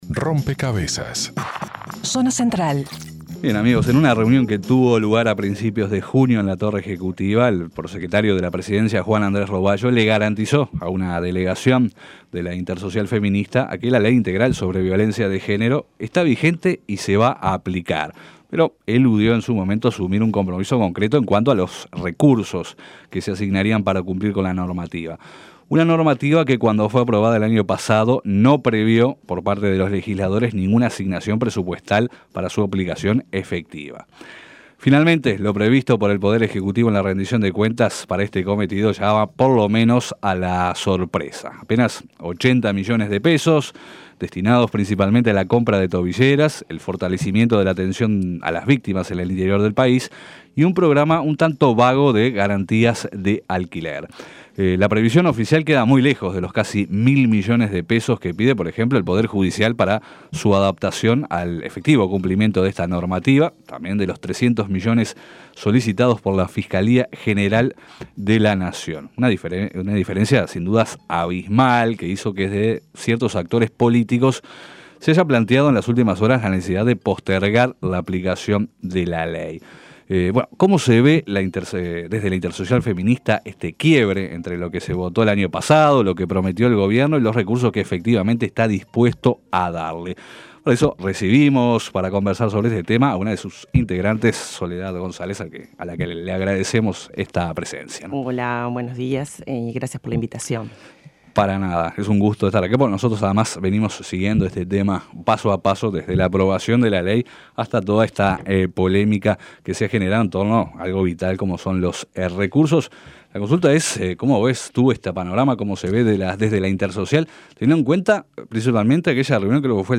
Entrevistada en Rompkbzas